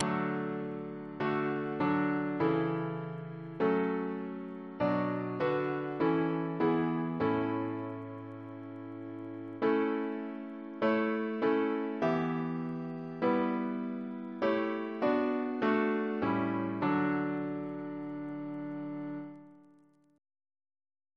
CCP: Chant sampler
Double chant in D Composer: Henry Smart (1813-1879) Reference psalters: ACB: 130; ACP: 221; CWP: 108; RSCM: 121